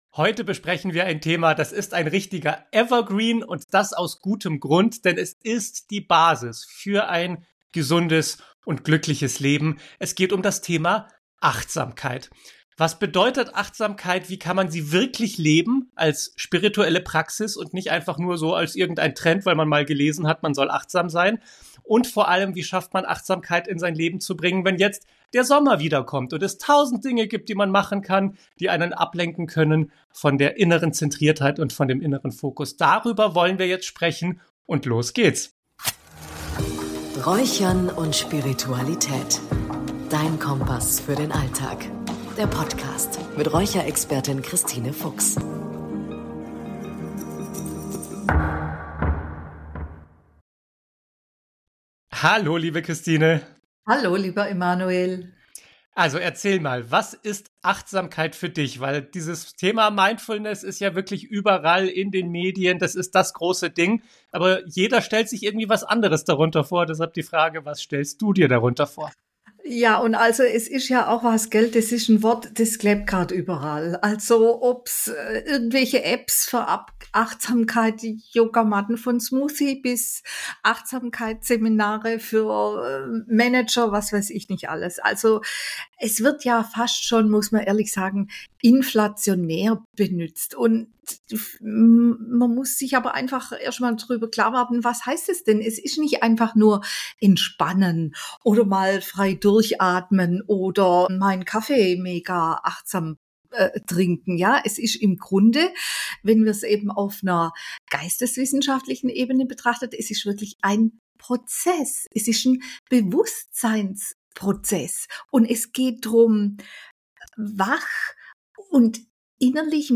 Zum Abschluss gibt es eine tiefgehende Meditation mit einem wunderschönen Bild: Du bist der Himmel – und deine Gedanken sind die Wolken, die vorüberziehen.